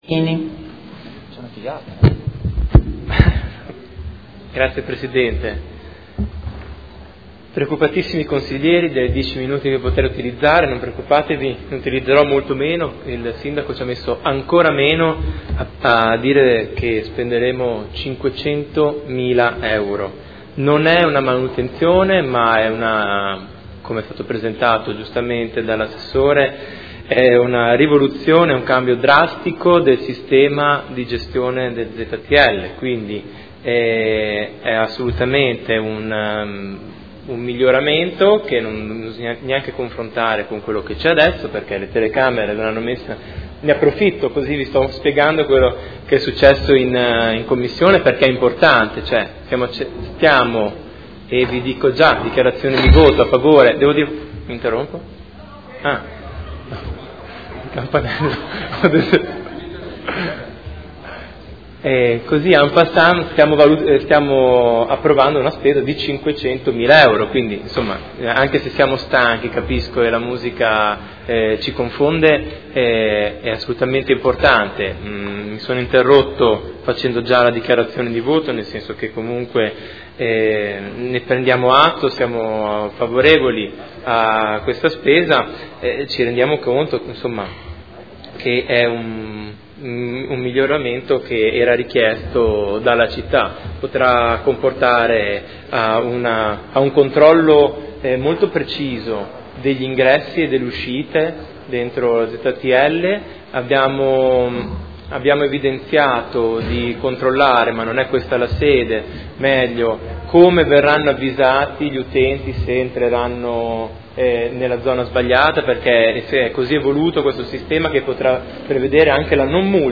Marco Chincarini — Sito Audio Consiglio Comunale